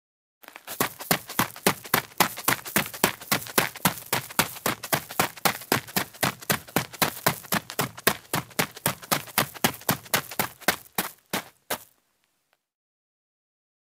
Sonneries » Sons - Effets Sonores » Course à pied